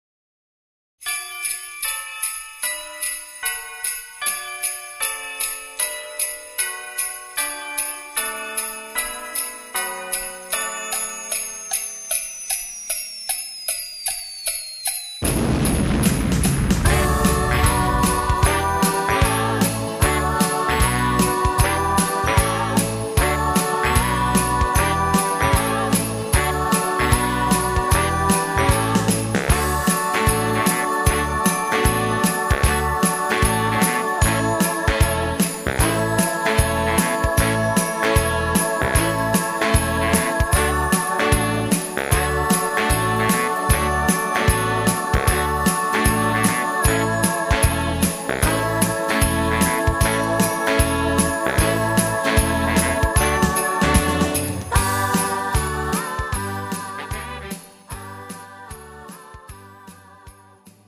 고음질 반주